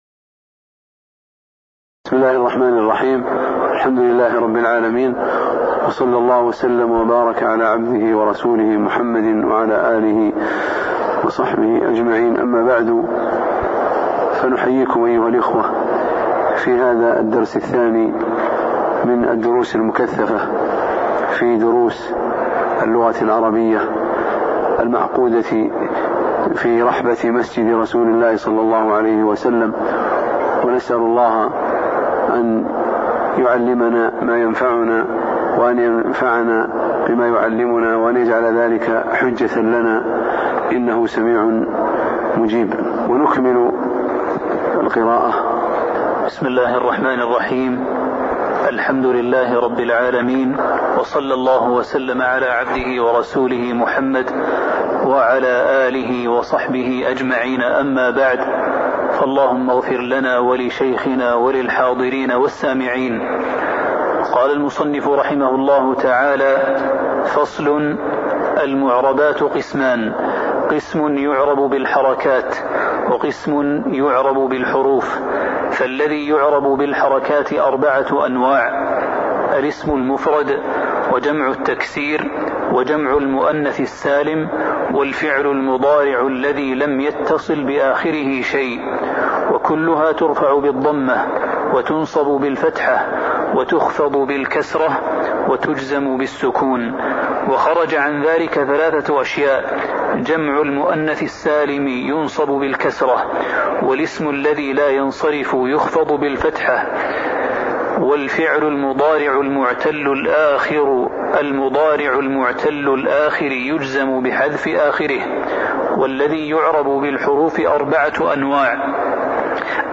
تاريخ النشر ٤ صفر ١٤٤٠ هـ المكان: المسجد النبوي الشيخ